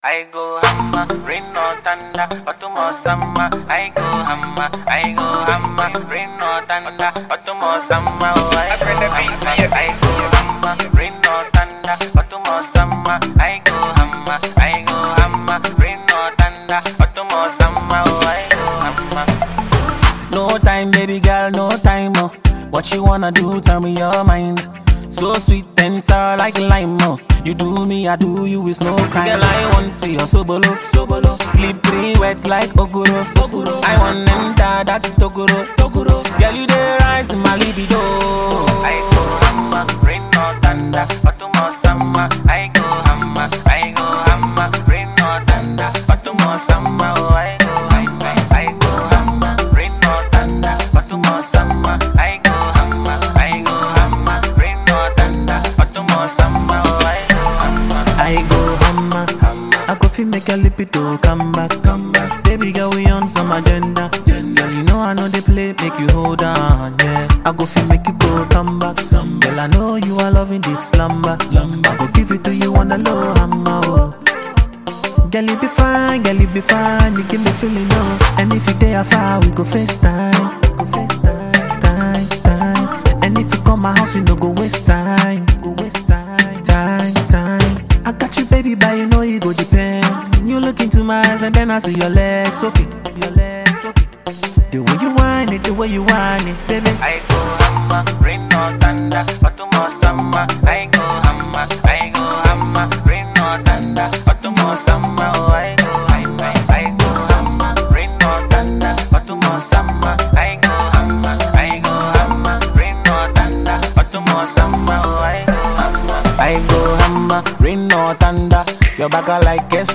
Ghanaian Music Duo